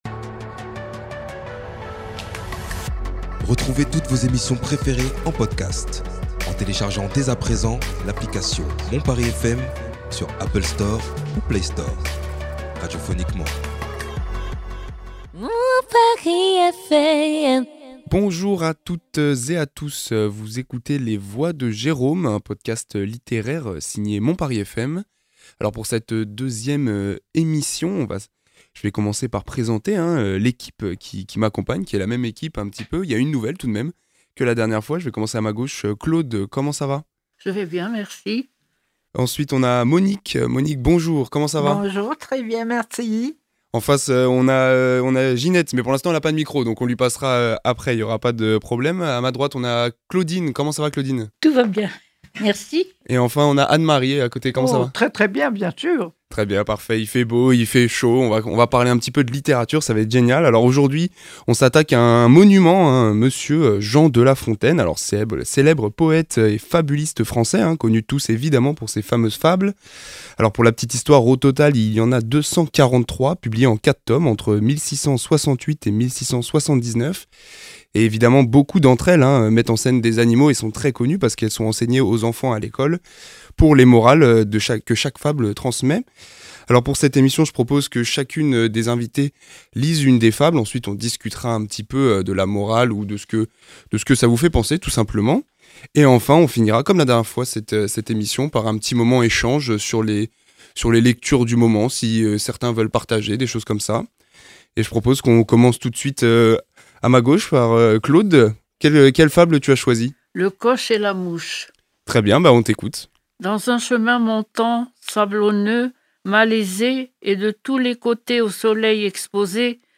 Les résidentes de GER'Home à Courbevoie nous partagent des extraits de fables de La Fontaines qu'elles ont choisis.